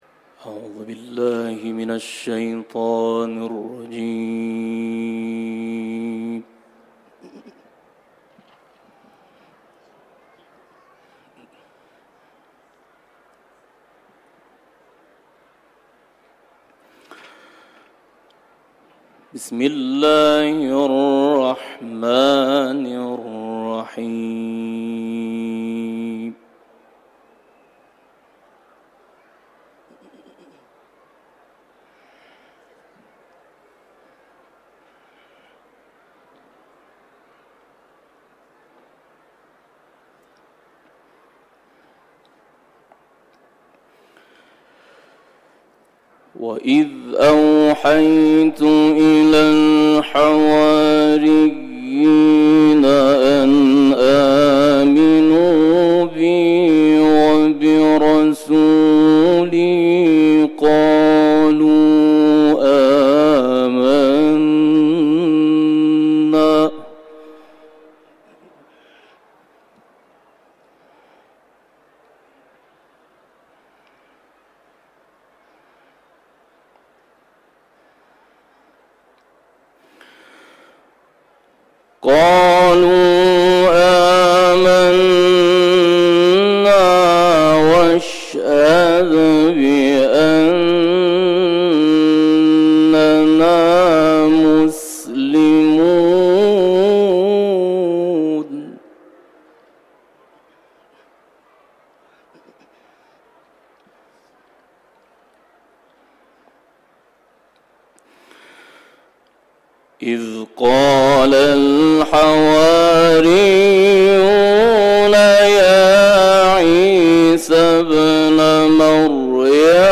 تلاوت